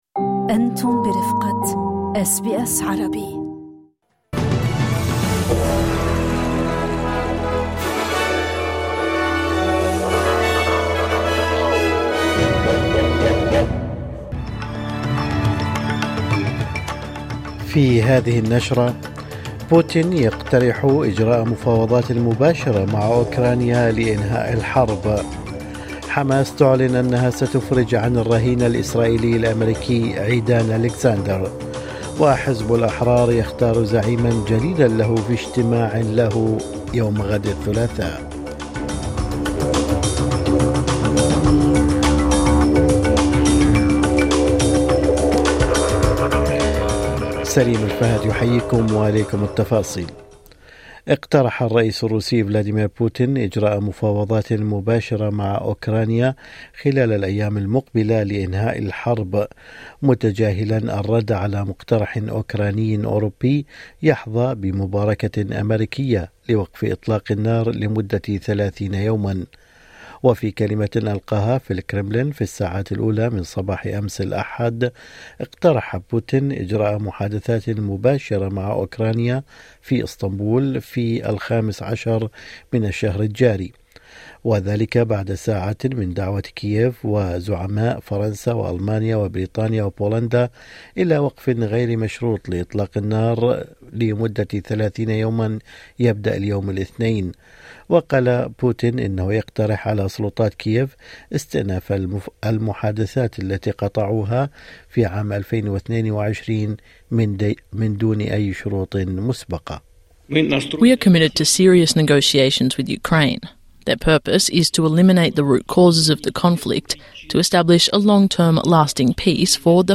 نشرة اخبار الصباح 12/5/2025